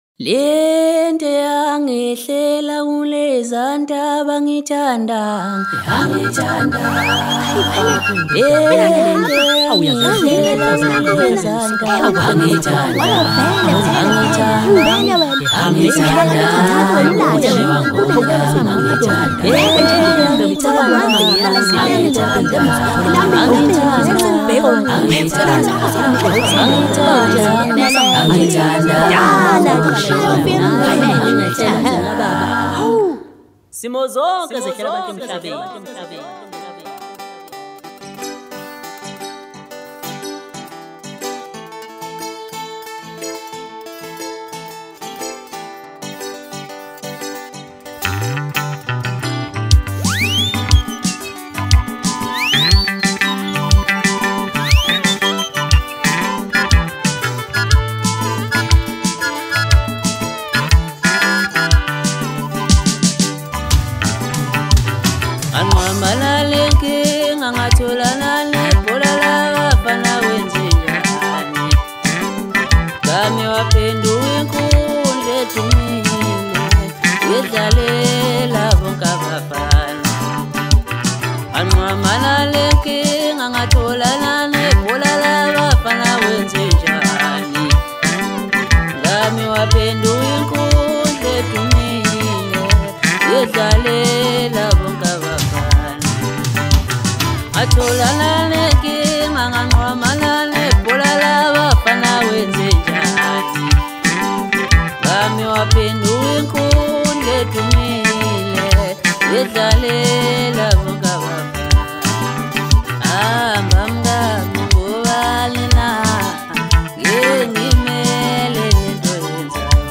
For every maskandi lover